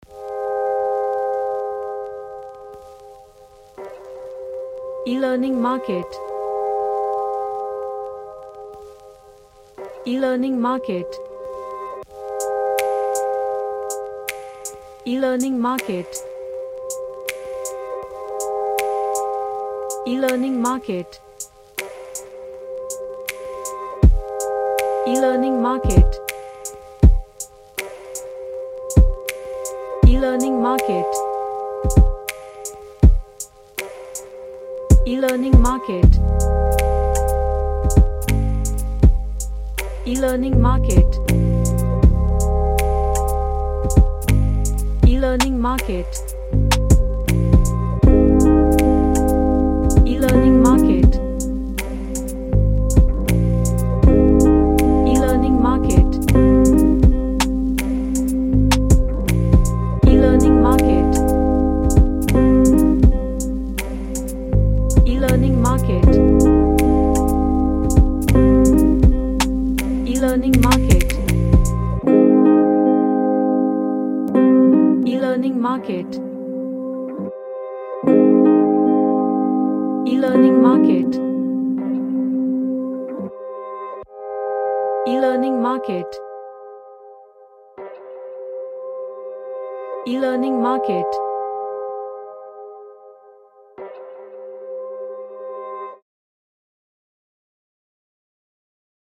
An ambient chill lofi track
Chill Out